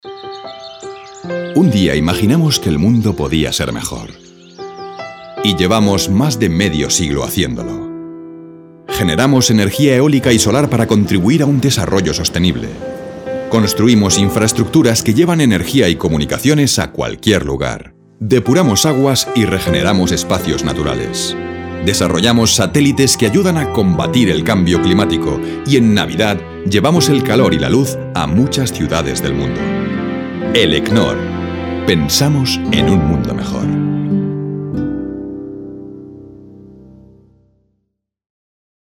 kastilisch
Sprechprobe: Werbung (Muttersprache):
Excellent corporate voice. Warm, Kind, Friendly and Expressive.